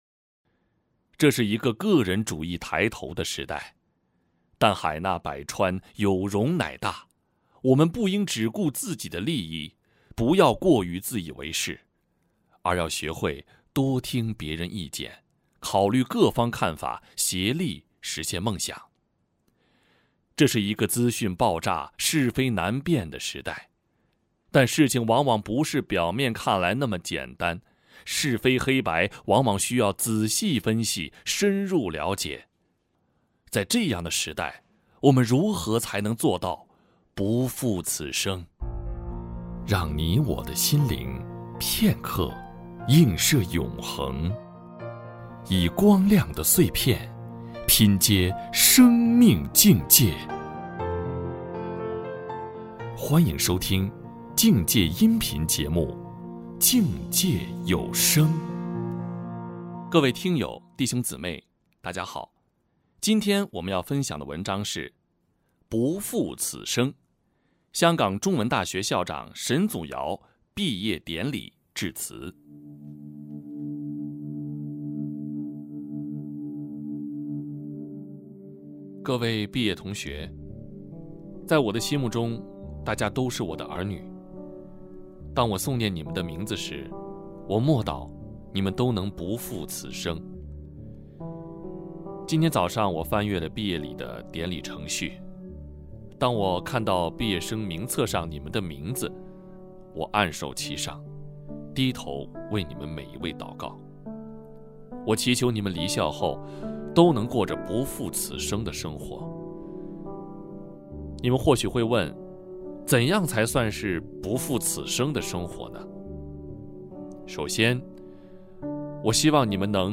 不-负-此-生——香港中文大学校长沈祖尧毕业典礼致辞（音频）.mp3